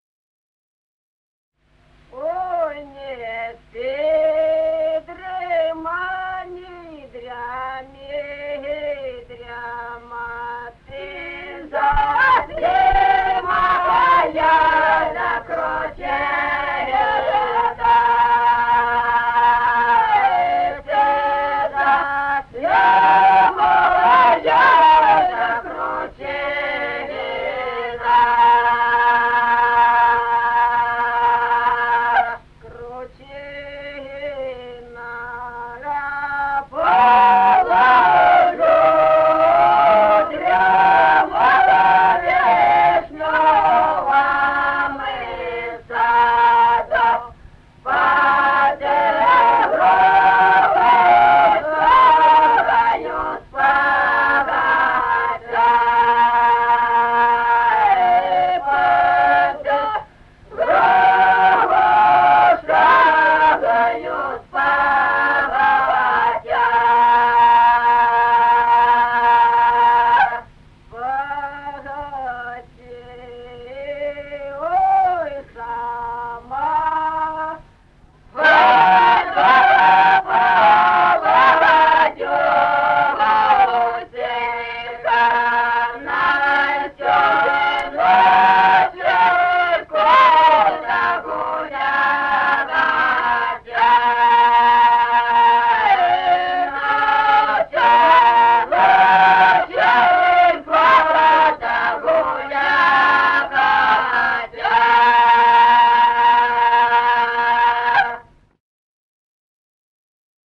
Музыкальный фольклор села Мишковка «Ой, не спи, дрема», лирическая.